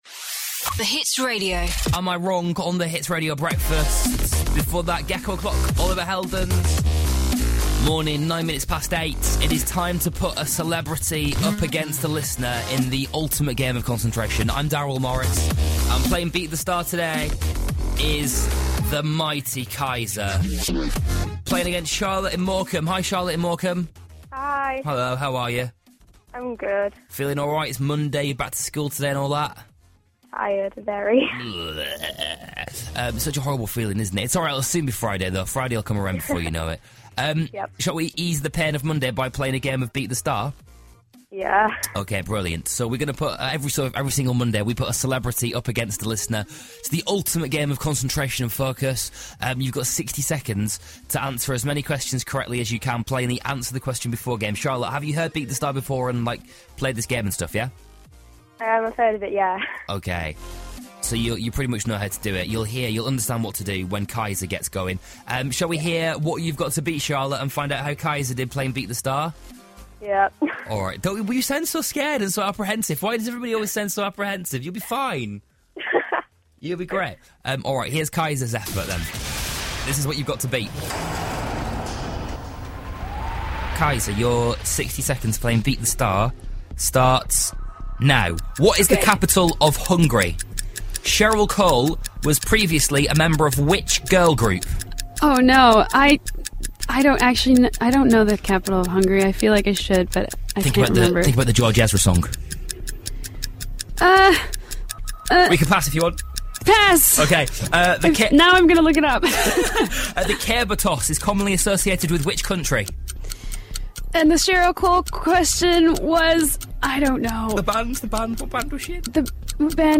Kiesza is the latest celeb to play along in Beat the Star on The Hits Radio breakfast show with Darryl Morris.